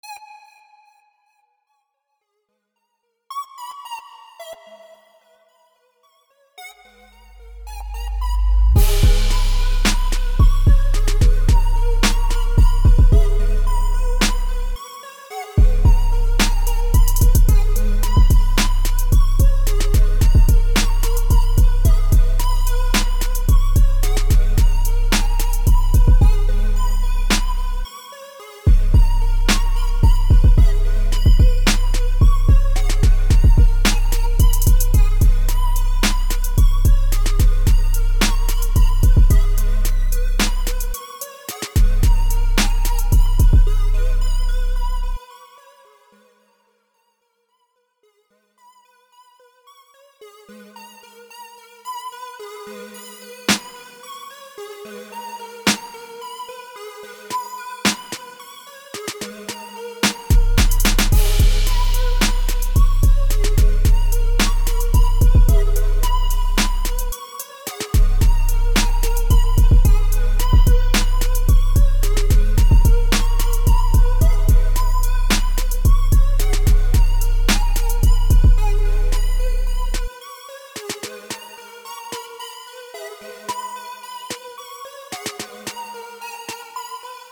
Скачать Минус